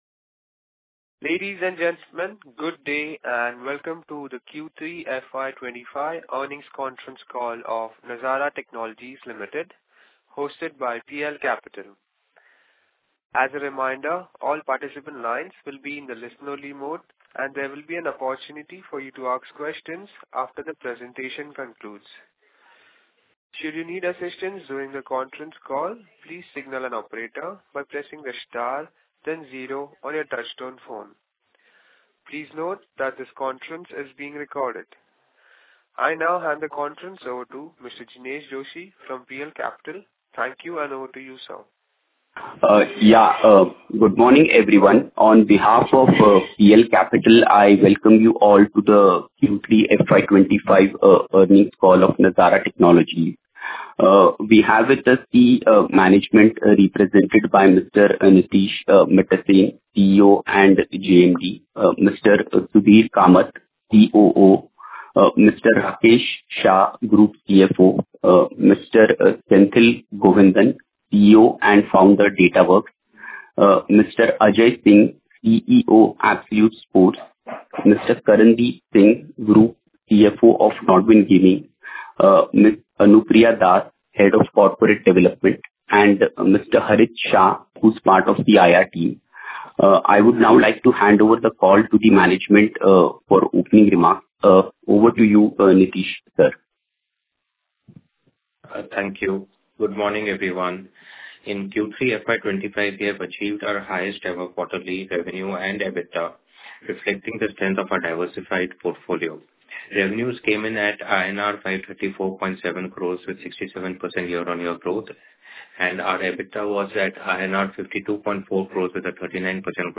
Nazara+Technologies+Q3FY25+Conference+Call+Audio.mp3